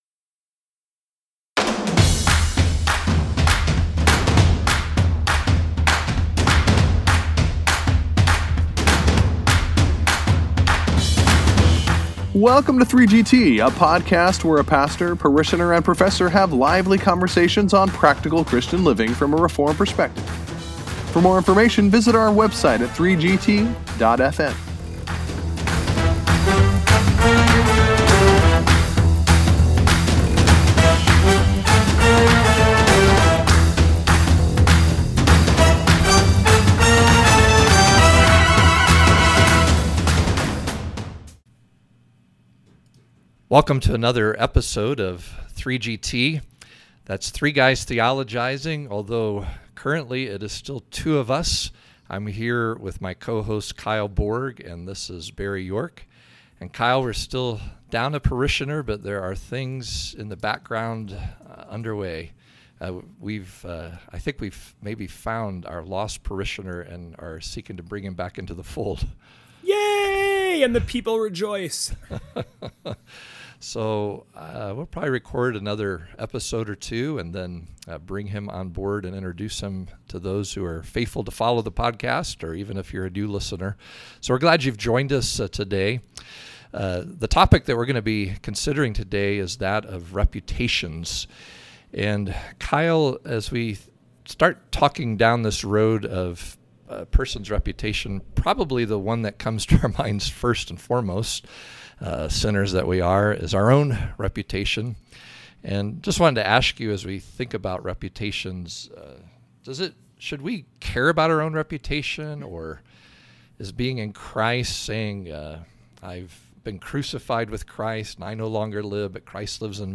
So tune in for this edifying discussion!